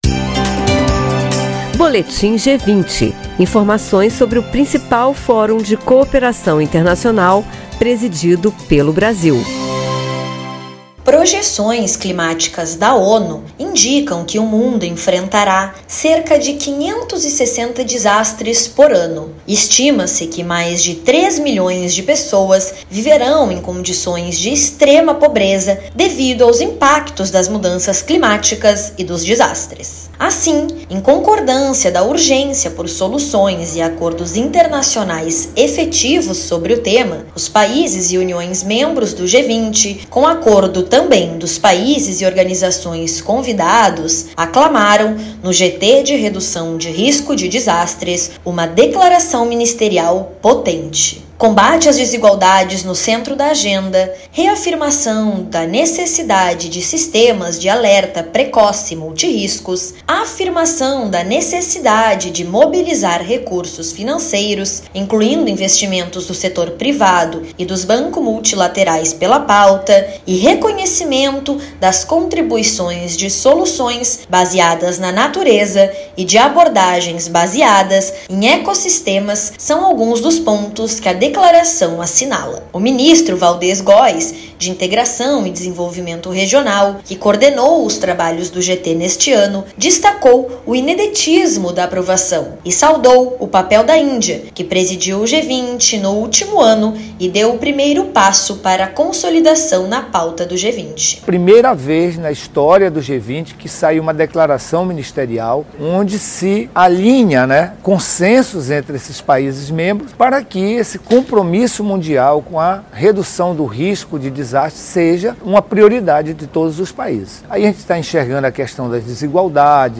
Com atividades no Boulevard Olímpico, no centro da capital fluminense, o evento de 14 a 16/11 inclui a participação de movimentos sociais e a entrega de documento final ao G20. Ouça a reportagem e saiba mais.